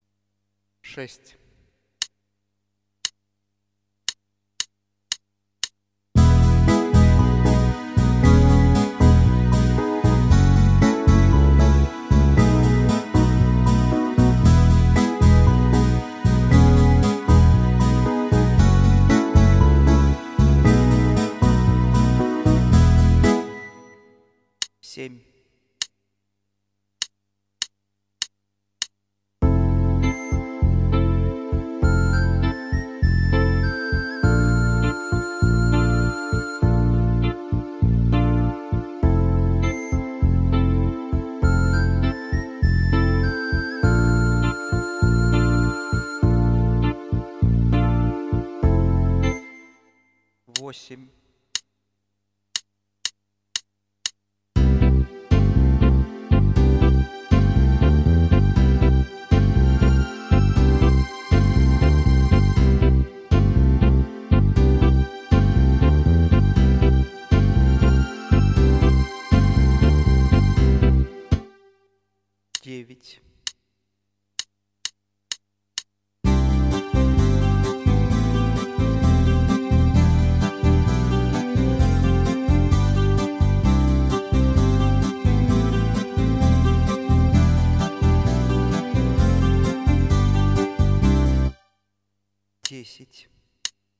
Пока курю, что стоит заказать. вот учебные нотки... как пример Посмотреть вложение 229890 и аудио из драм установки паттернов Вложения untitled.mp3 untitled.mp3 1,8 MB · Просмотры: 399